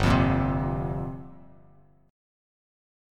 Gm11 chord